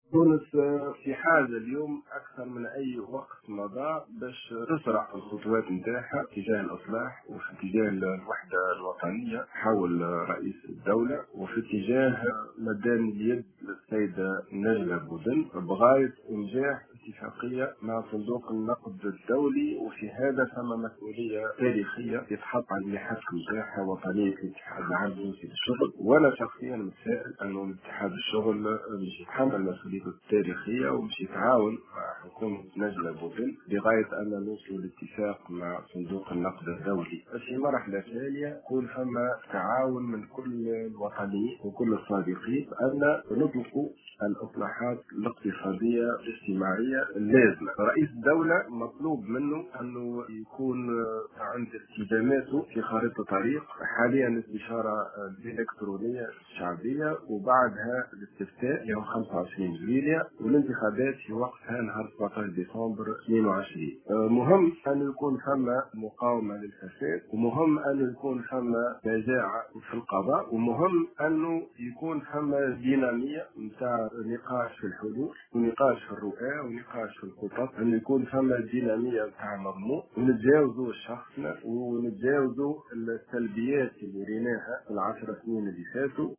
S’exprimant ce vendredi au micro de Tunisie Numérique, le leader démissionnaire du parti Ennahdha, Imed Hammemi a assuré que la Tunisie a aujourd’hui plus que jamais besoin d’accélérer les démarches de réformes et établir l’unité nationale autour du président de la République Kais Saied et de tendre la main à la cheffe du gouvernent Najla Bouden.